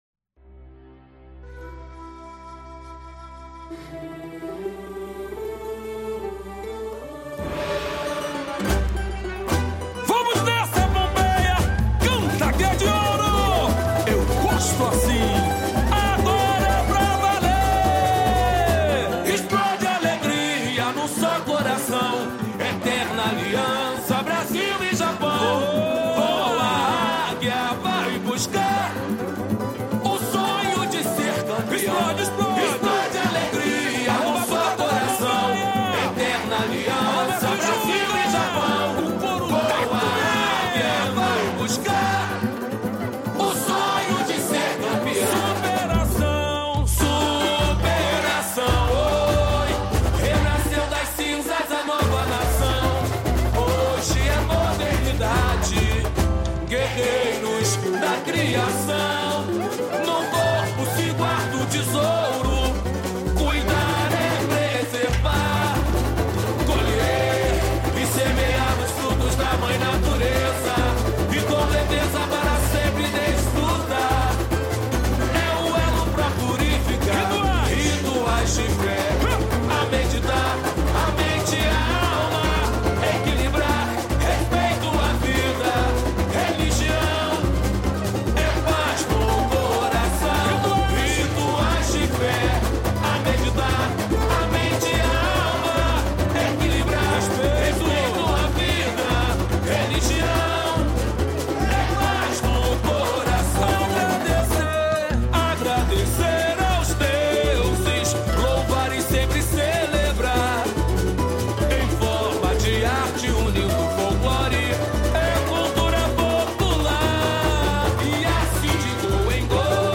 Interprete: